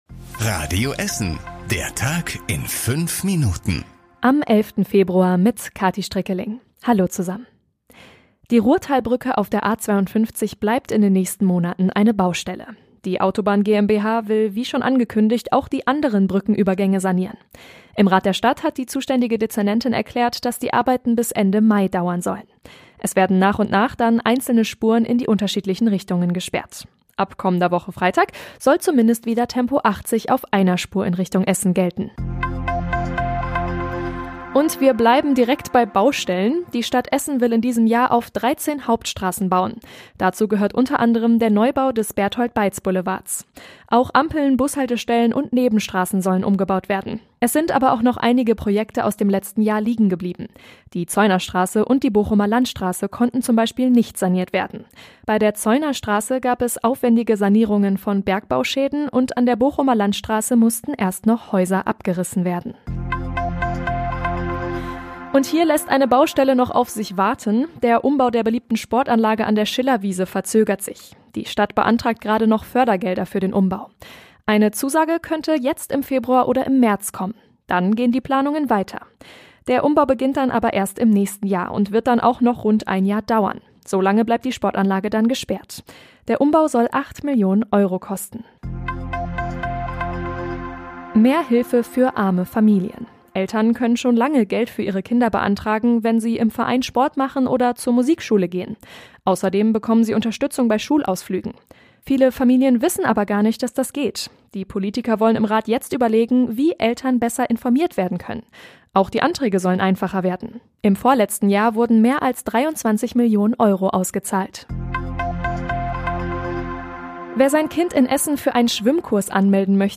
Die wichtigsten Nachrichten des Tages in der Zusammenfassung
Nachrichten